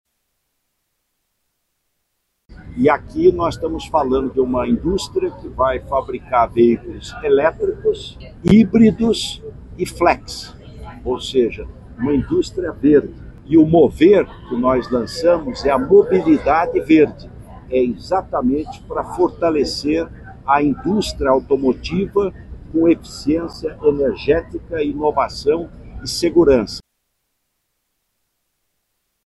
Alckmin destacou o foco na sustentabilidade das duas montadoras e mencionou o Programa Mover, lançado pelo Governo Federal em 2023, com o objetivo de reduzir imposto de quem polui menos como incentivo à mobilidade verde.